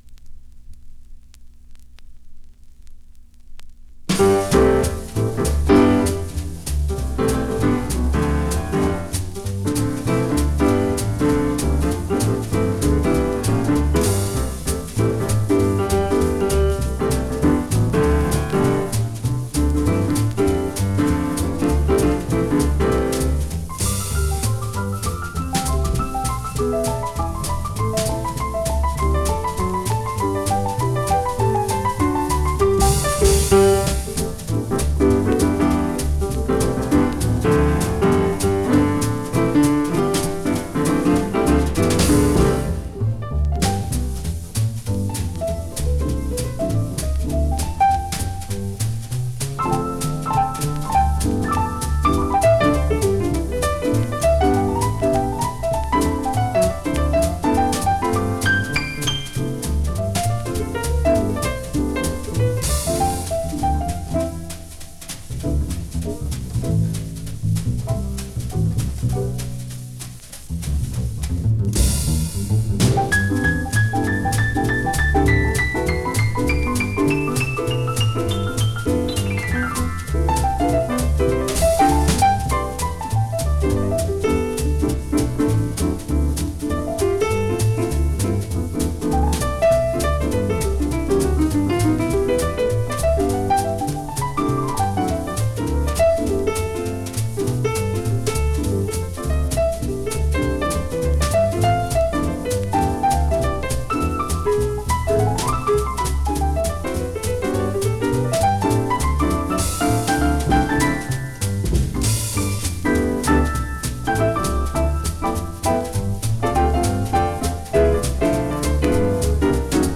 Recorded:  1960 in Paris, France